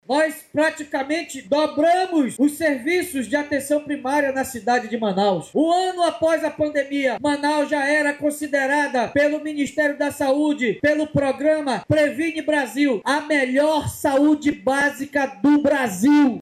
Durante o pronunciamento, o prefeito David Almeida, destacou os resultados alcançados pela gestão na área da saúde básica, afirmando que Manaus passou de 47% para 92% de cobertura em Atenção Primária nos últimos anos, além de receber reconhecimento nacional e internacional.